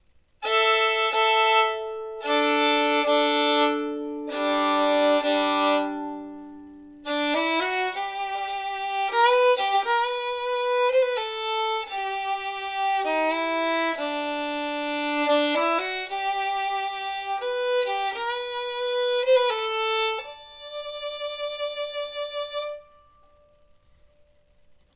New Handmade Violin / Fiddle Outfit with case & bow - $425.00
I would classify this one as medium loud in volume, with a bright tone quality.